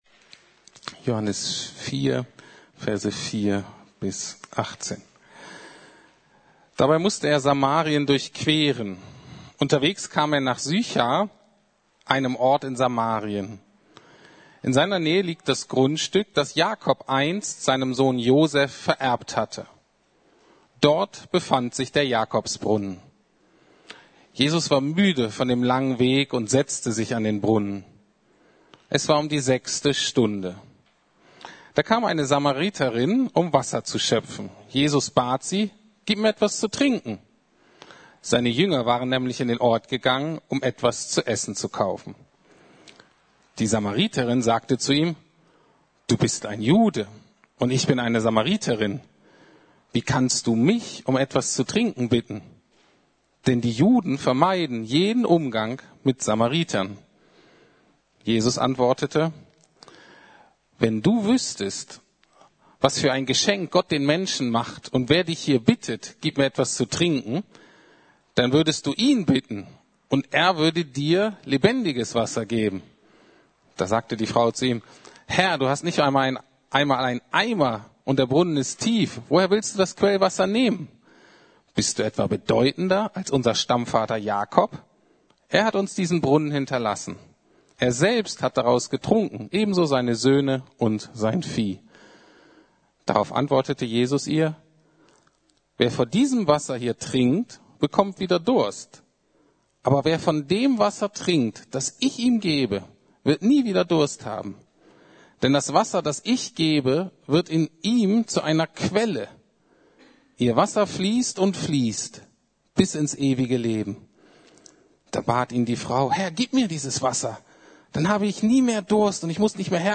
Der Kreis der Liebe Gottes ~ Predigten der LUKAS GEMEINDE Podcast